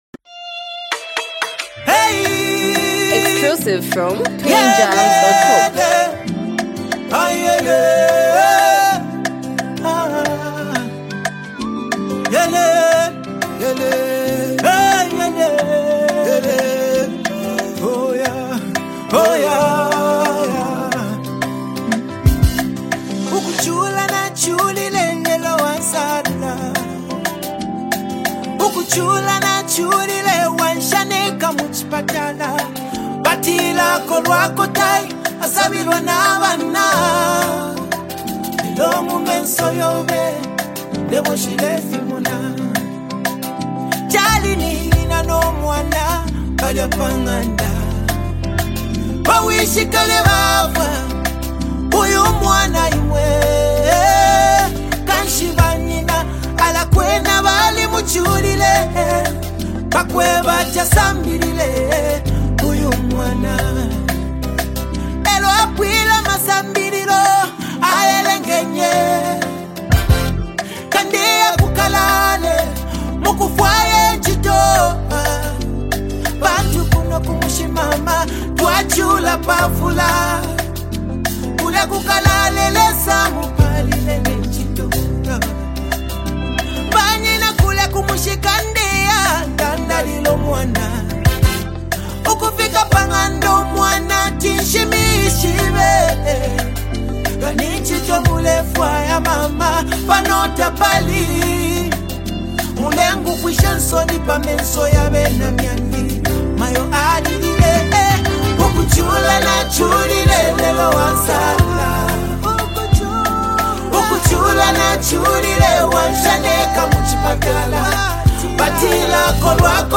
blending local Zambian sounds with modern Afro influences.
A culturally inspired and rhythmically rich track